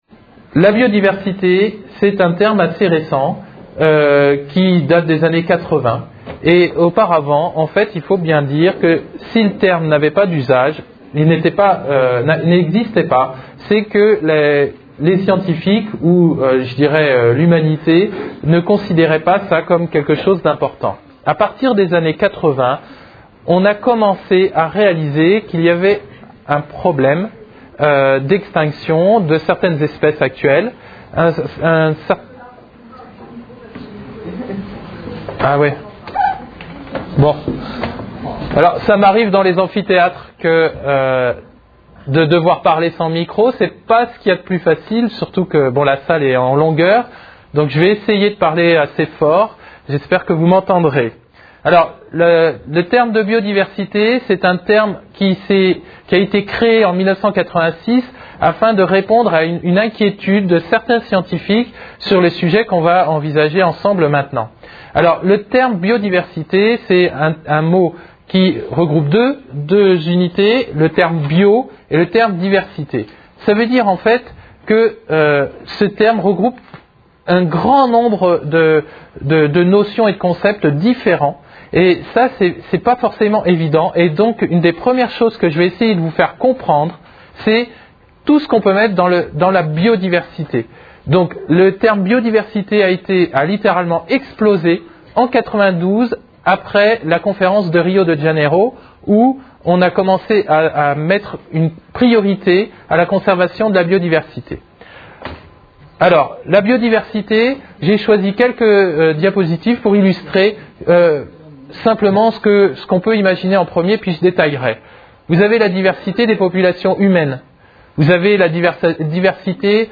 Une conférence